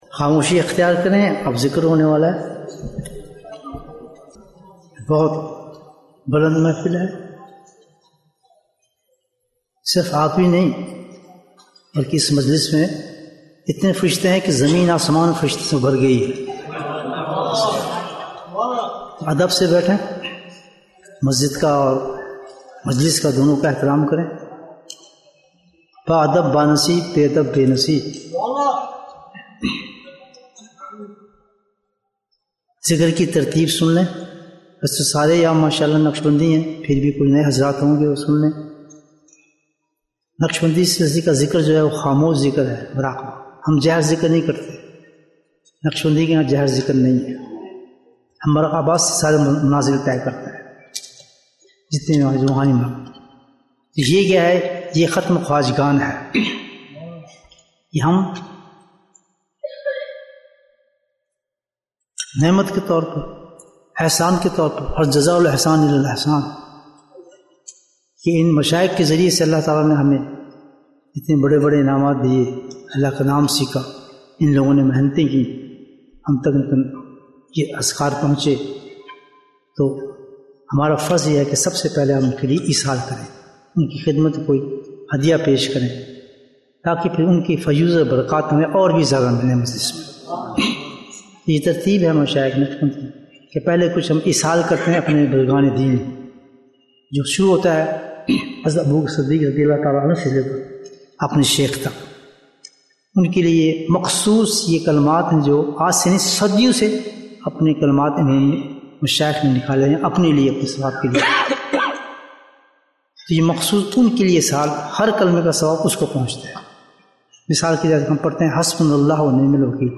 سالانہ اجتماع ذکر مجلس ۲۰۲۴ Bayan, 63 minutes25th December, 2024